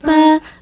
Note 1-D#.wav